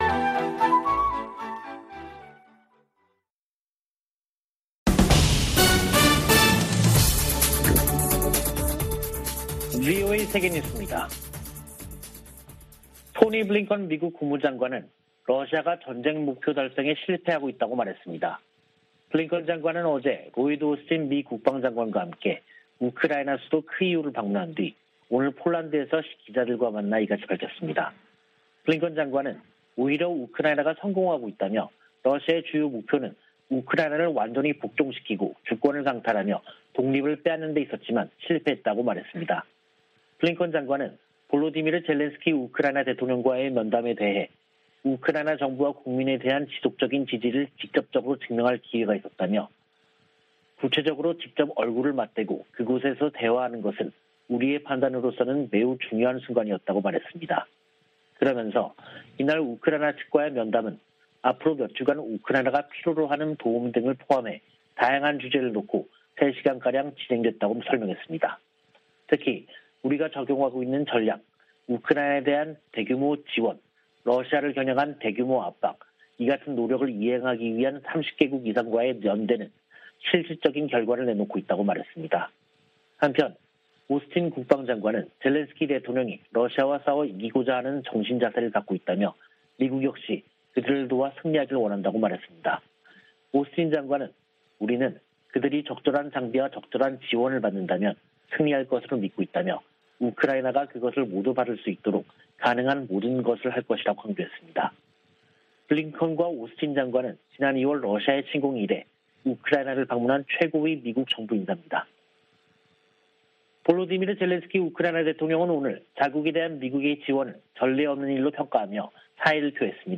VOA 한국어 간판 뉴스 프로그램 '뉴스 투데이', 2022년 4월 25일 3부 방송입니다.